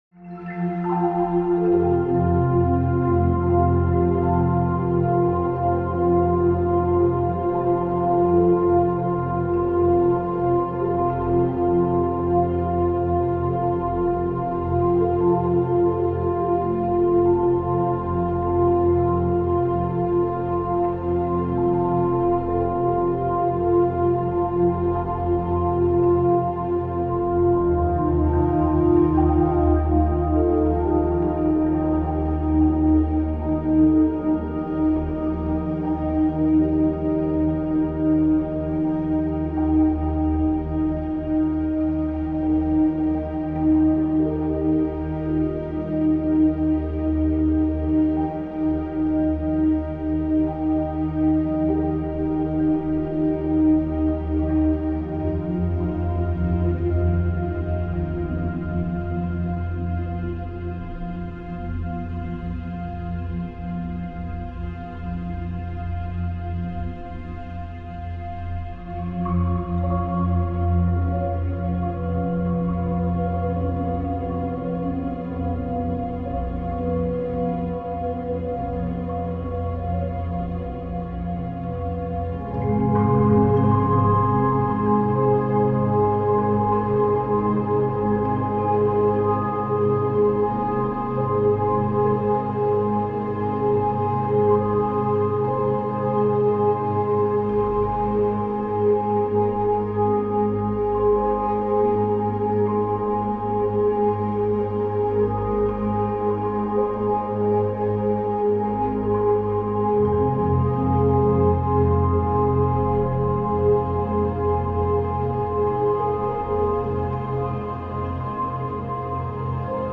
forest2.opus